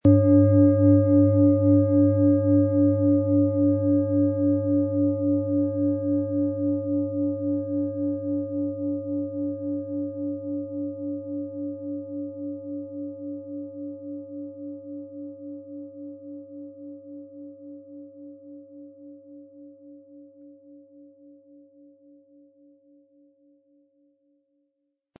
Die Planetenklangschale Uranus ist handgefertigt aus Bronze.
• Mittlerer Ton: Mars
Im Sound-Player - Jetzt reinhören können Sie den Original-Ton genau dieser Schale anhören.
PlanetentöneUranus & Mars & Biorhythmus Körper (Höchster Ton)